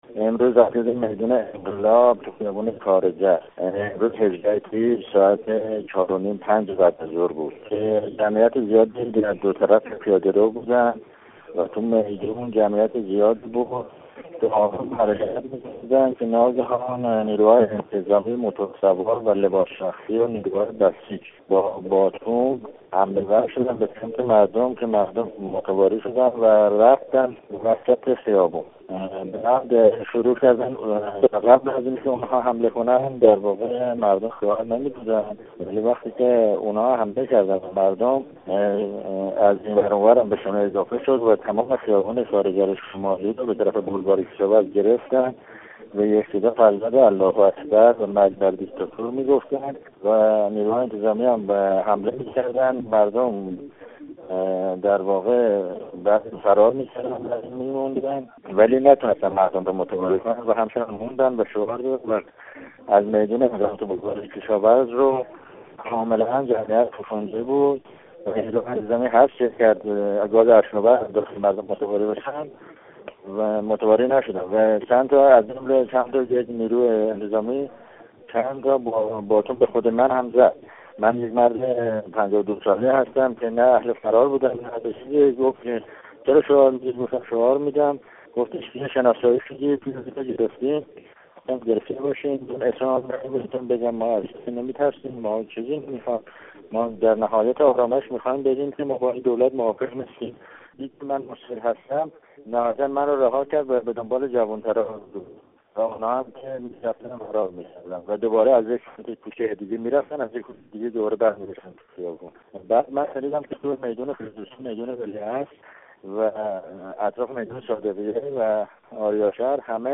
گفت‌وگو با یک شاهد عینی -۳